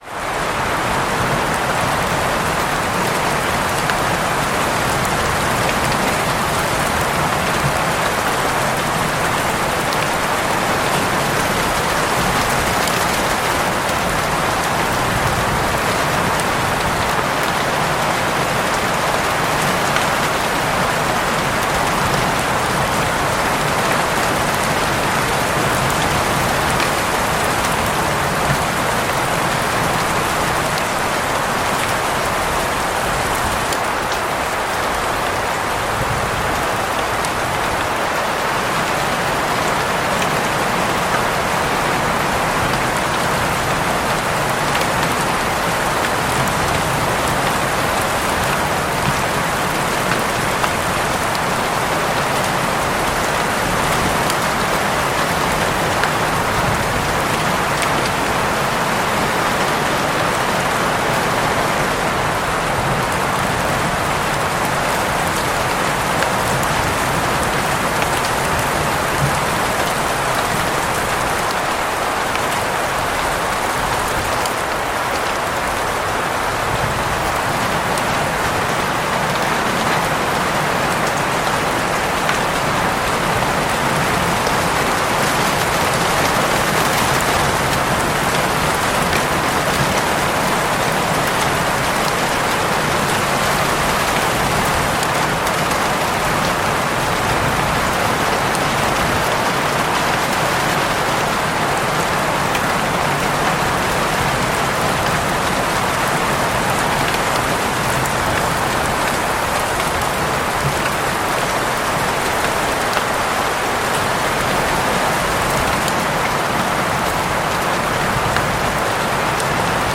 Lluvia con Viento y Trueno para Dormir, Centrar tu Energía y Recuperar Serenidad
Sonidos de Lluvia, Lluvia para Dormir, Lluvia Relajante, Lluvia Suave